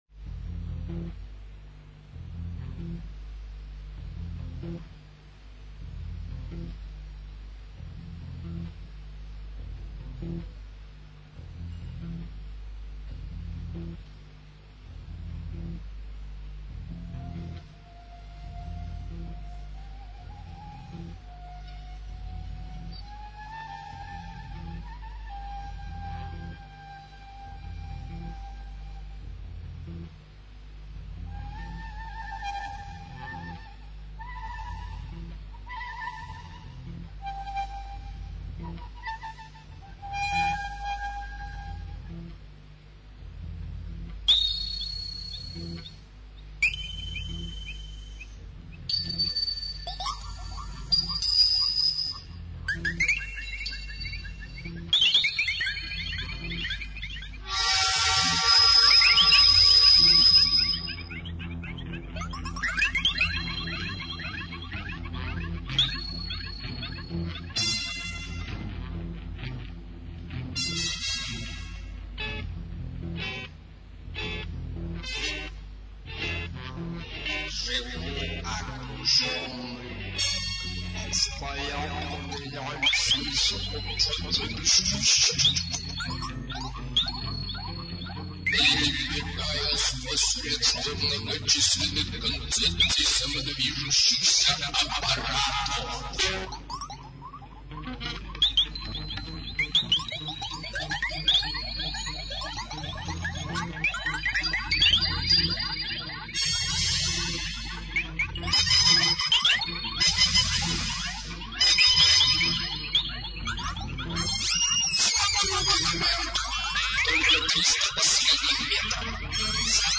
Рок Русский рок Альтернативный рок